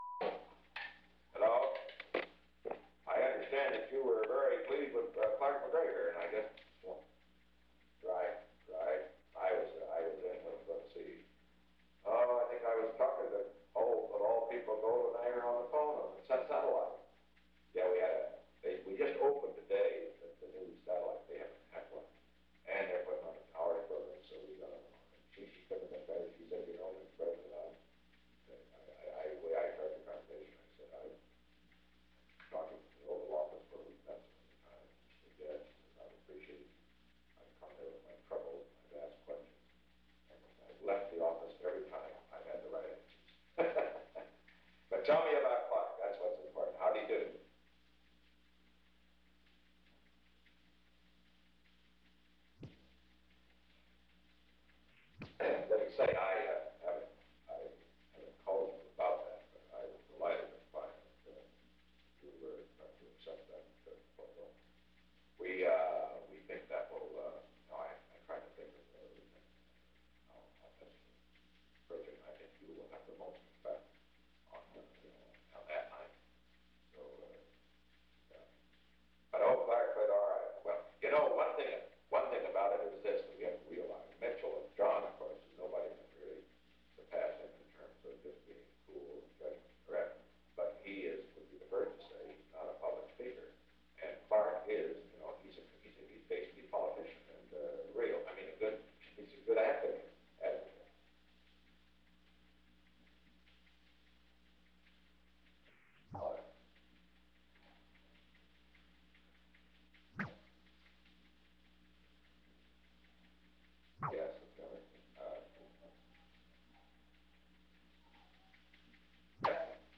Secret White House Tapes
Conversation No. 352-2
Location: Executive Office Building
The President talked with Nelson A. Rockefeller.